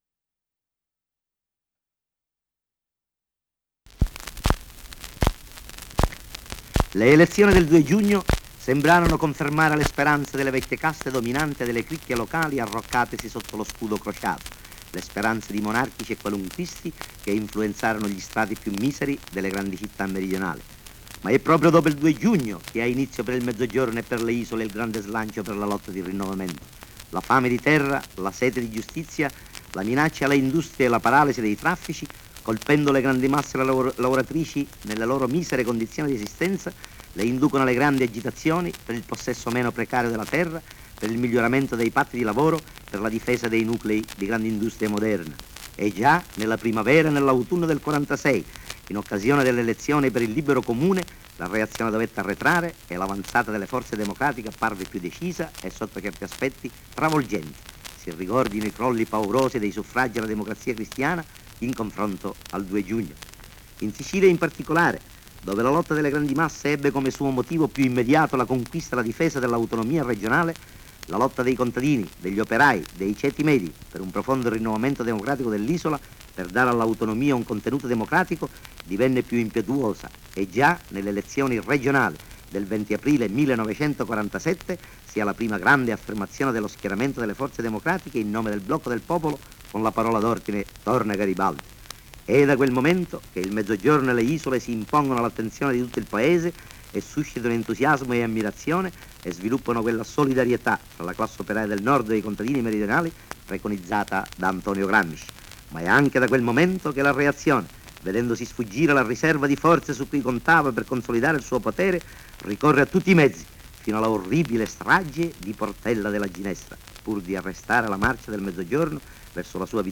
Digitalizzazione vinile 78 giri (2)
Digitalizzazione di un vinile 78 giri contenente un saluto e il discorso di Girolamo Li Causi per l’appello al voto per il Fronte Democratico Popolare.